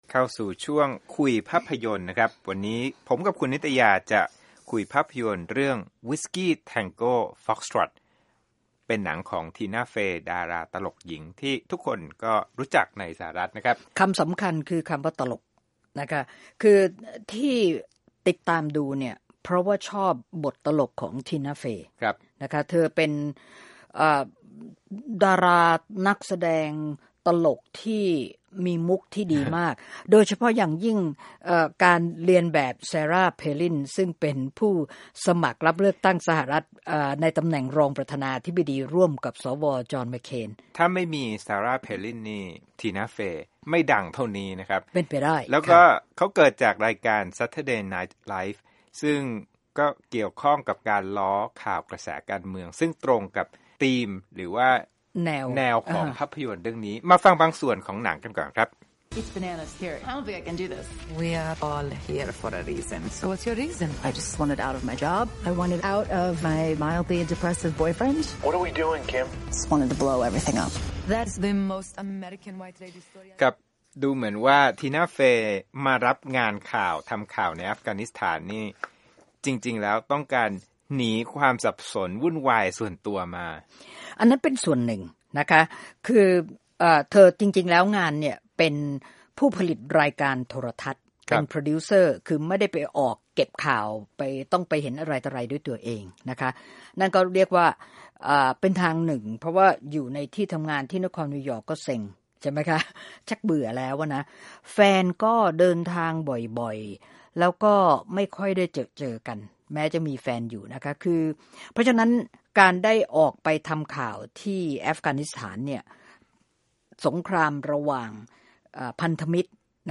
วิจารณ์ภาพยนตร์